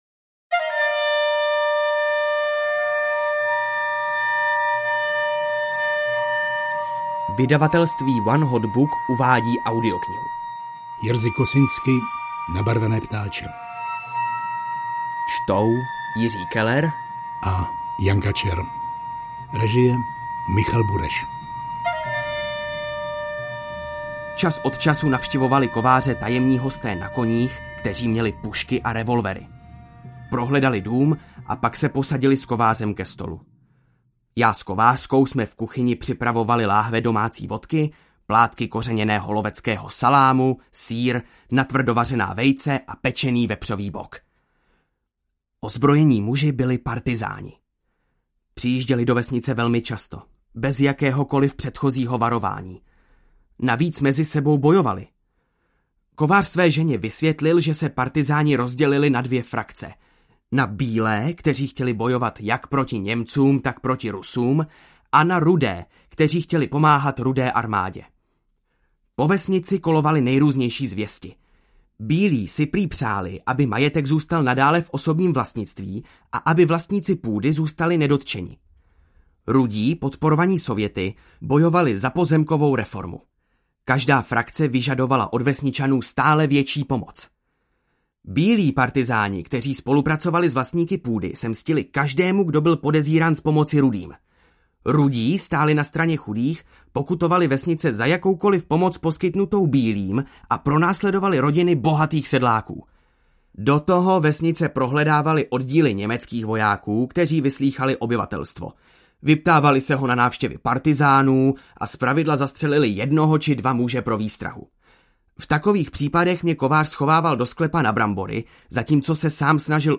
Nabarvené ptáče audiokniha
Ukázka z knihy